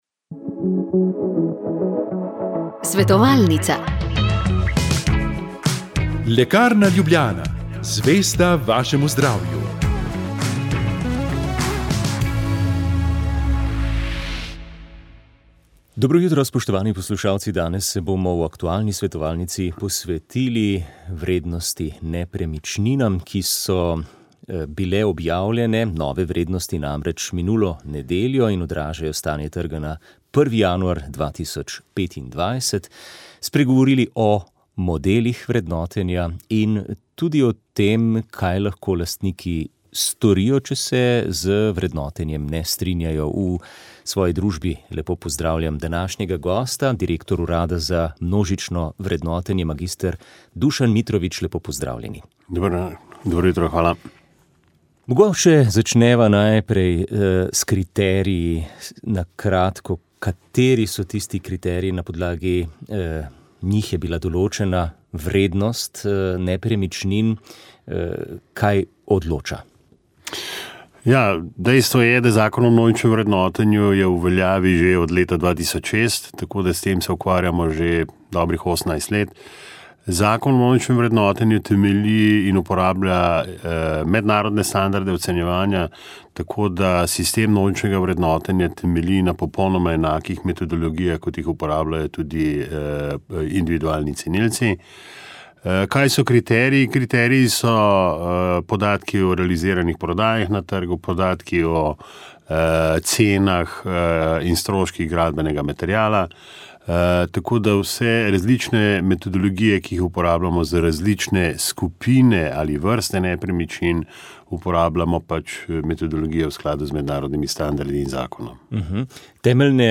S predstavniki lokalne skupnosti, civilne iniciative in policije smo spregovorili o reševanju romske problematike na jugovzhodu države. Dotaknili smo se trenutnih varnostnih razmer, ukrepov v sklopu Šutarjevega zakona, dodatno predlaganih rešitev na področju socialne in delovne aktivacije ter pričakovanj različnih akterjev.